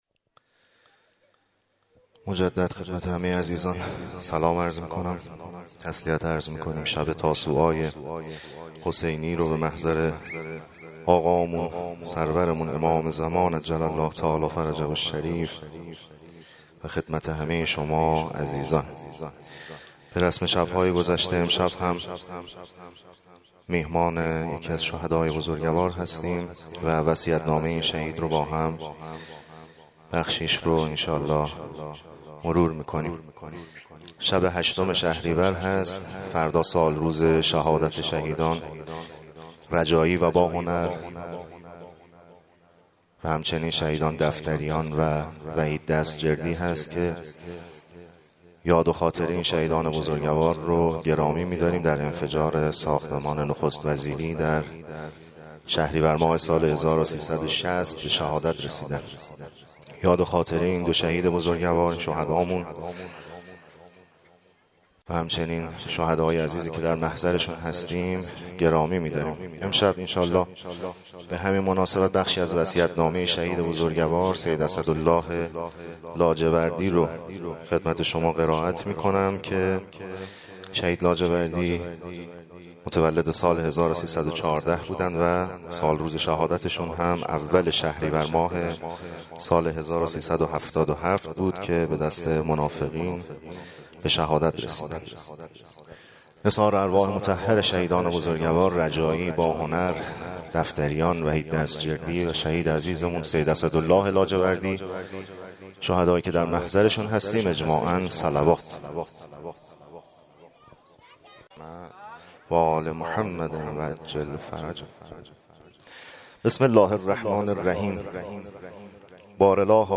گلزار شهدای گمنام شهرک شهید محلاتی
شب نهم محرم 99